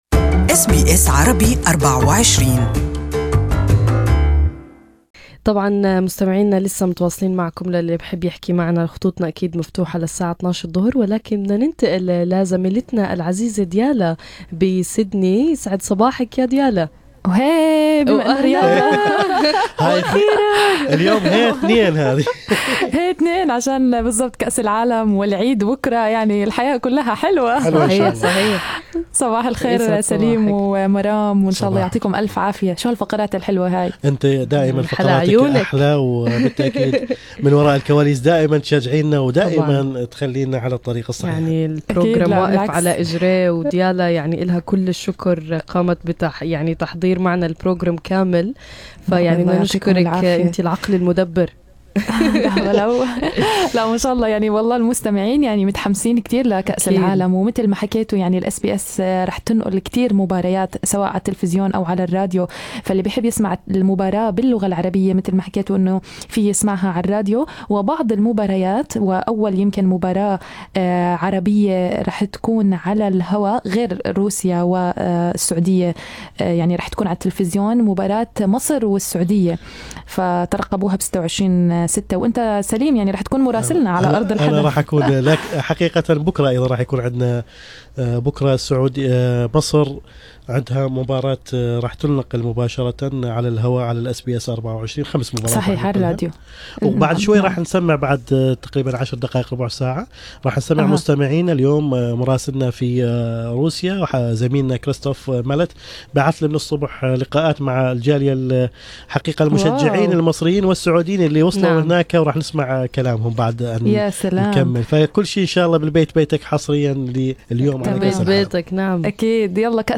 الاجابة في اللقاء التالي